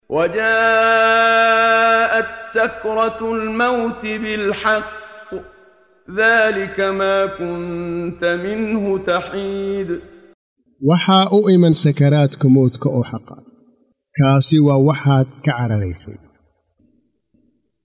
Waa Akhrin Codeed Af Soomaali ah ee Macaanida Suuradda Qaaf oo u kala Qaybsan Aayado ahaan ayna la Socoto Akhrinta Qaariga Sheekh Muxammad Siddiiq Al-Manshaawi.